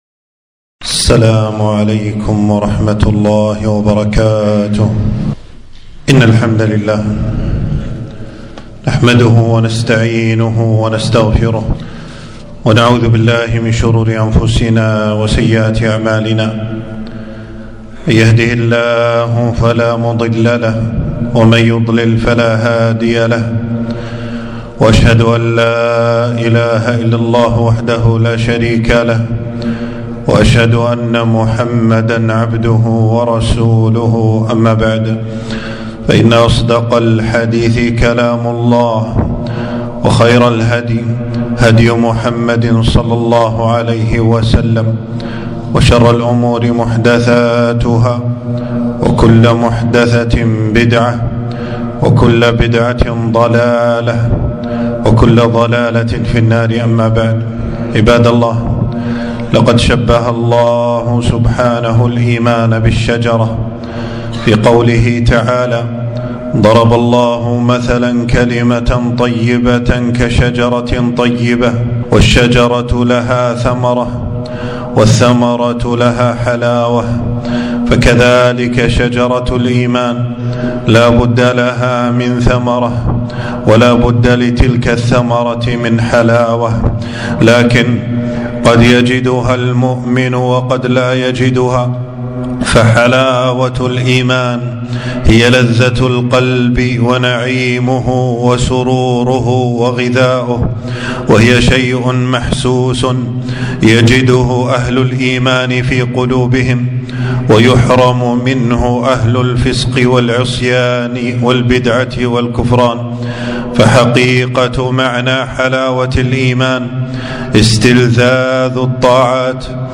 خطبة - حلاوة الإيمان